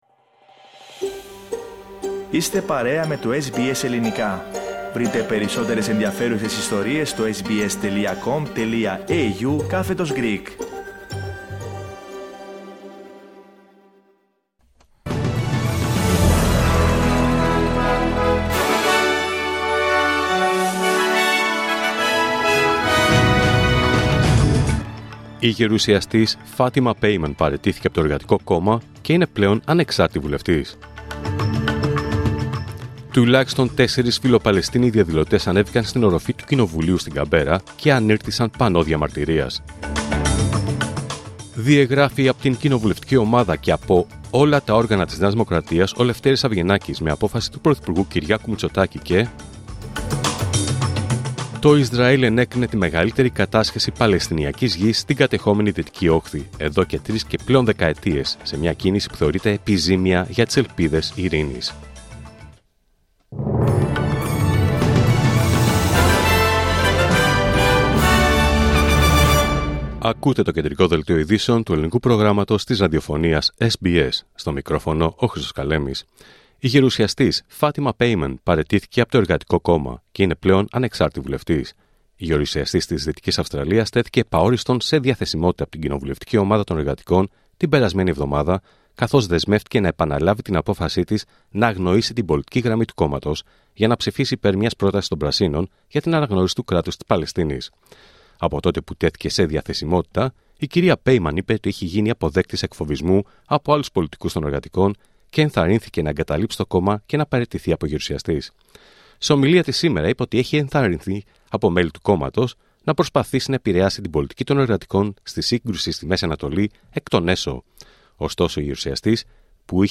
Δελτίο Ειδήσεων Πέμπτη 4 Ιουλίου 2024